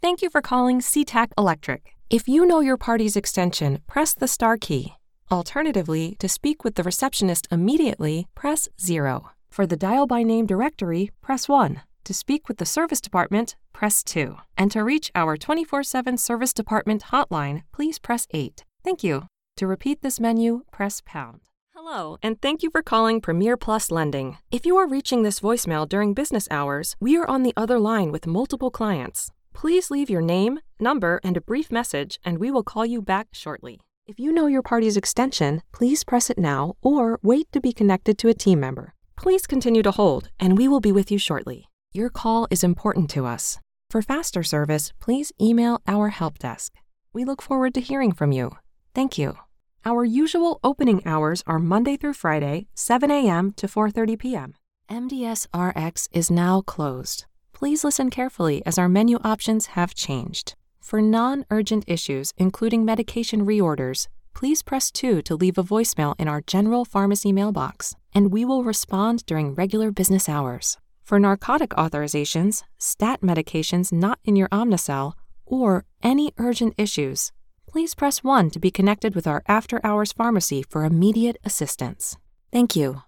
From commercials and explainer videos to e-learning, promos, and narration, I offer a warm, engaging sound designed to connect with your audience.
IVR Demo